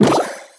alien_die_02.wav